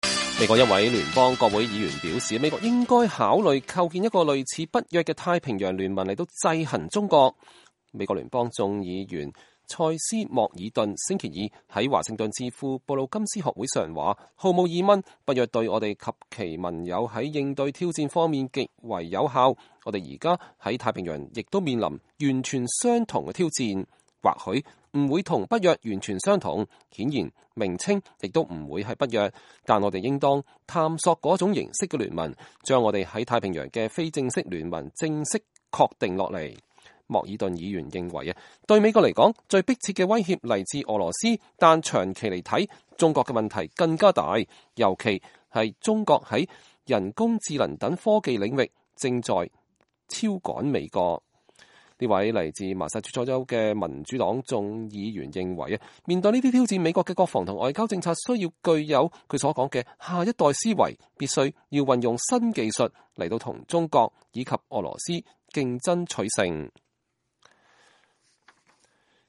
麻薩諸塞州聯邦眾議員穆爾頓2019年2月12日在布魯金斯學會發表演說。